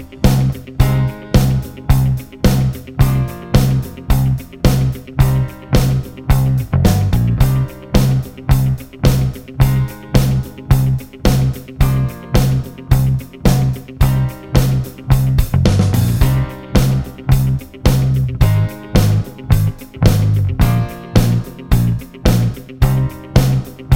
Two Semitones Down Rock 3:58 Buy £1.50